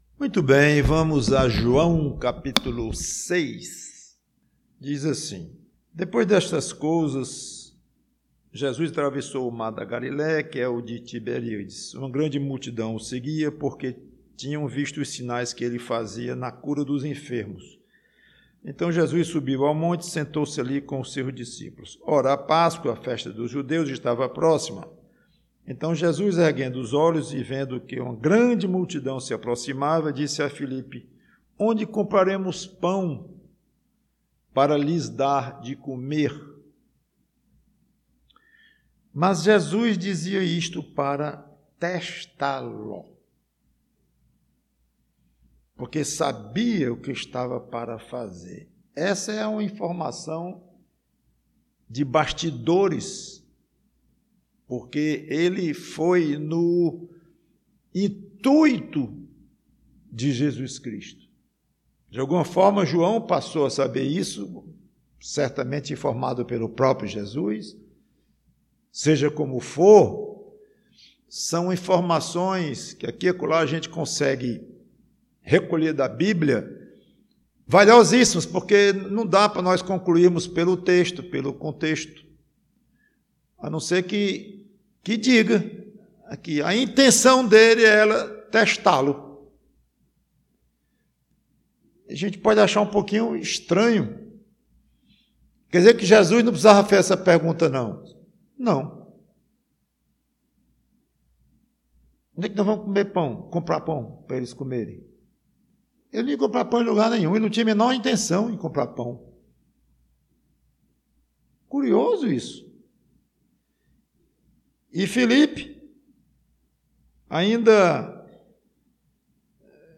PREGAÇÃO Testes de Deus: você tem sido aprovado?